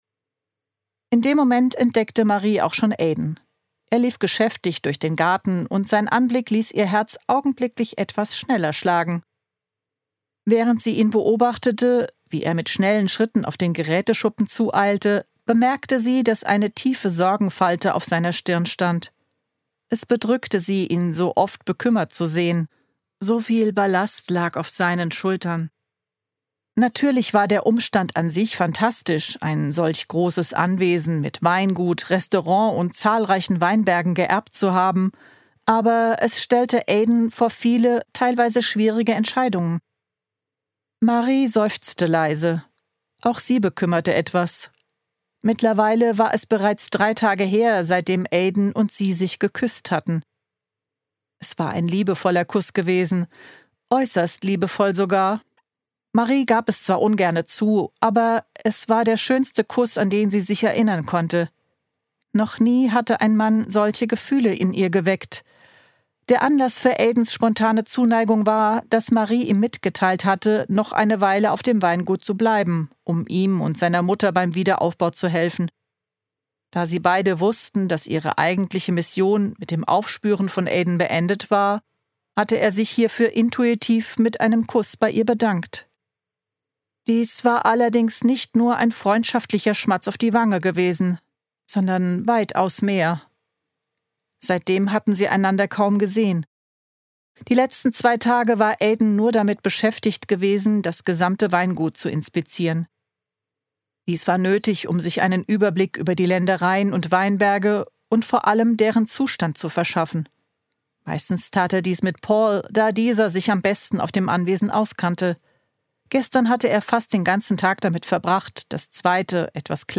Sprecherin: